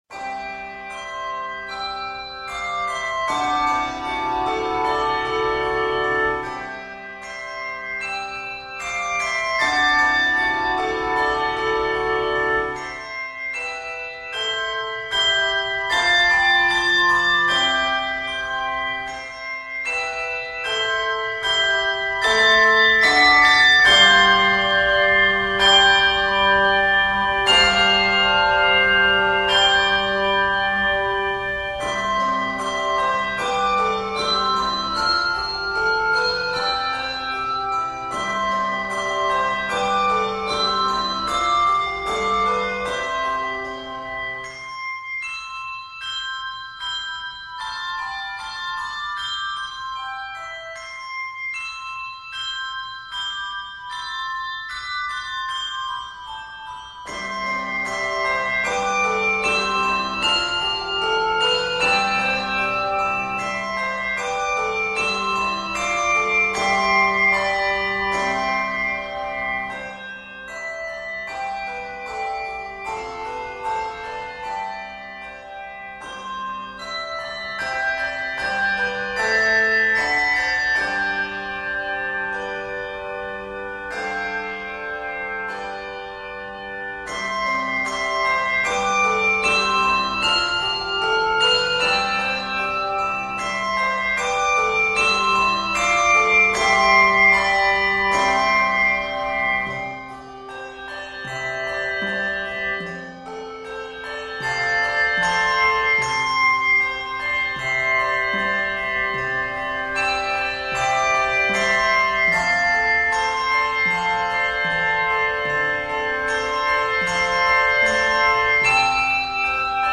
Scored in C Major